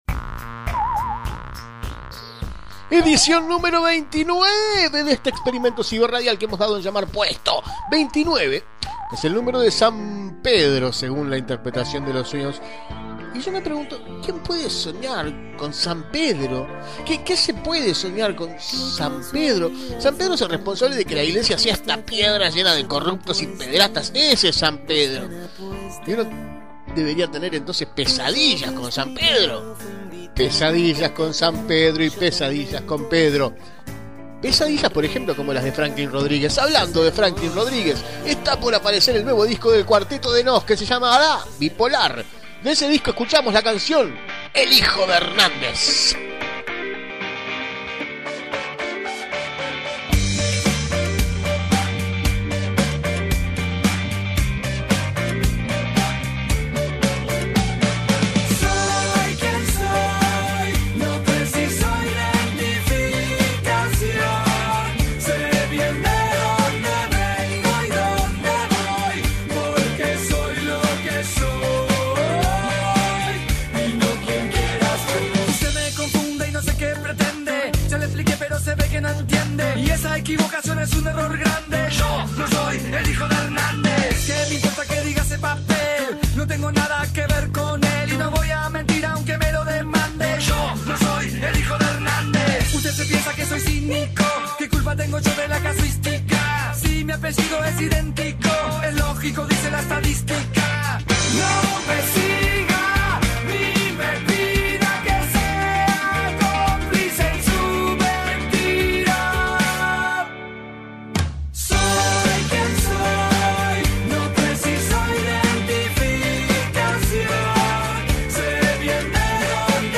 Episodio XLVII de "Puesto", el programa de radio online que tuvo Escanlar entre 2008 y 2009 en Montevideo Portal.